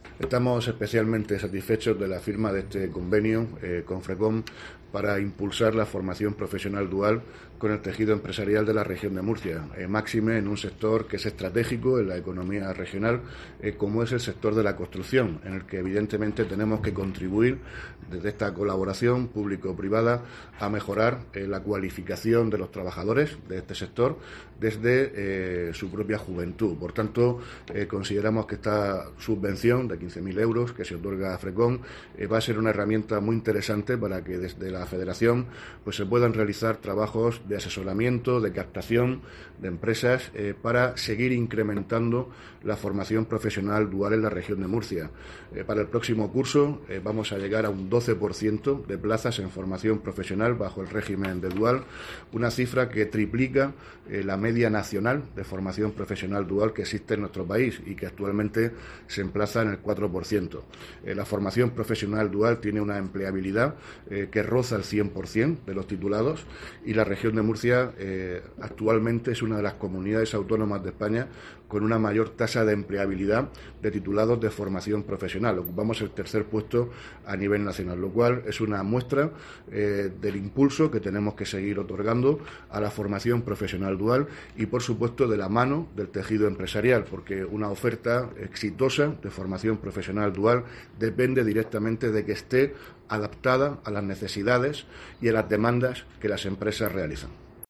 Víctor Marín, consejero de Educación en funciones